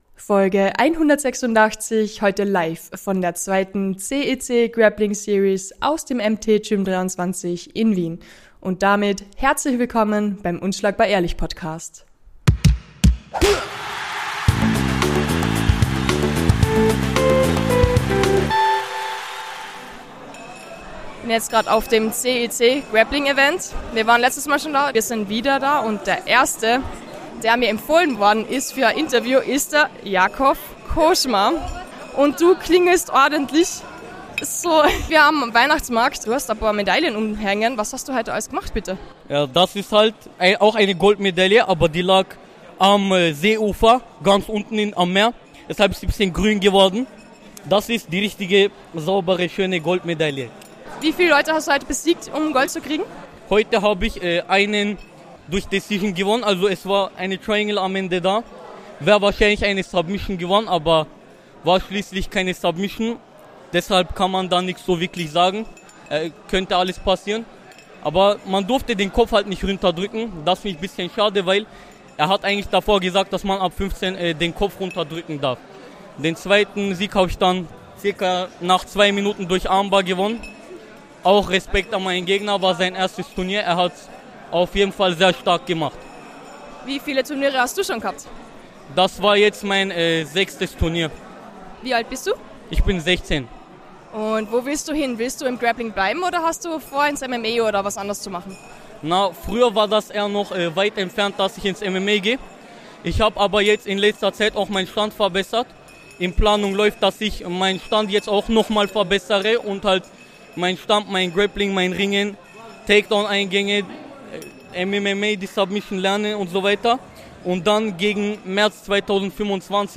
#186 Live von der CEC Grappling Series 2